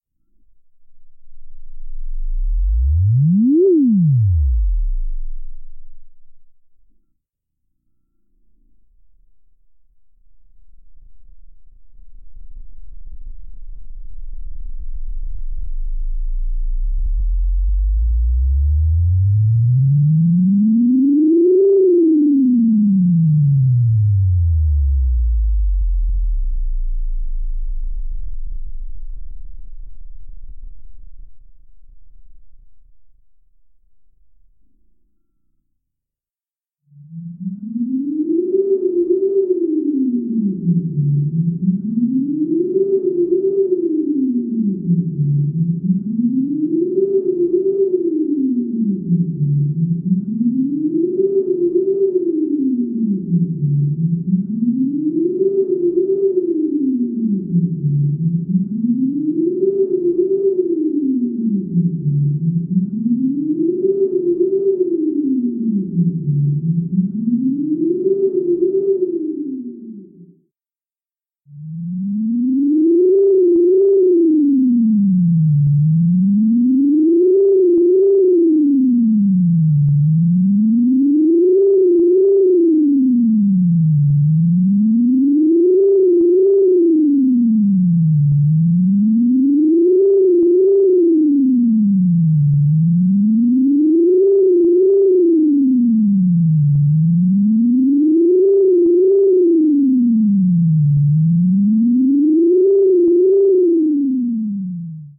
实验者 " 科学幻想的噪音
标签： 怪异 科幻 小说 迷幻 科学 科幻 科幻
声道立体声